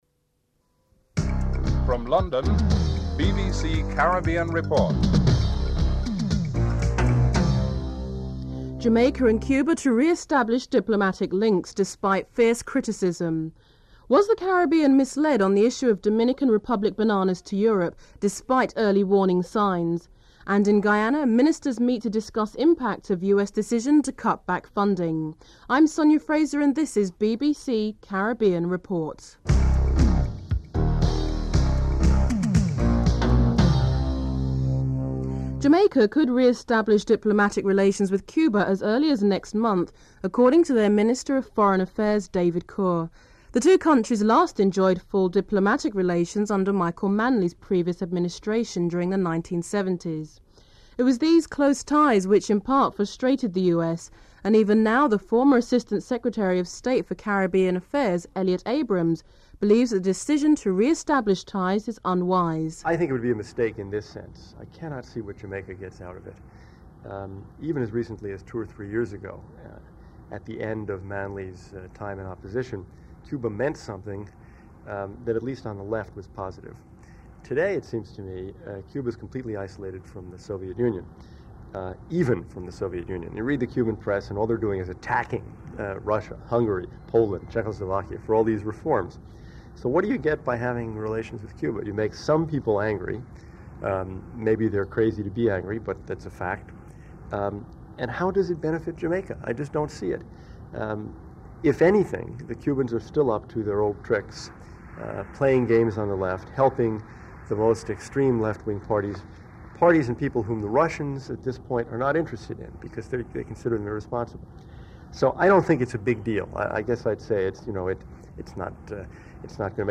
Includes musical interlude at the beginning of the report and the final segment ends abruptly.
1. Headlines (00:00-01:28)